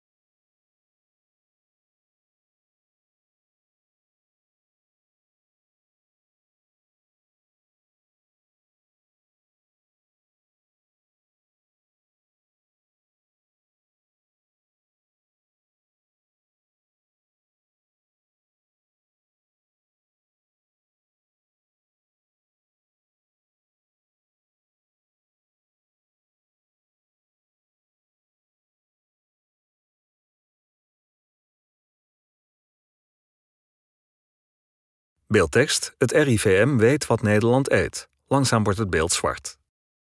RUSTIGE MUZIEK DIE WEGEBT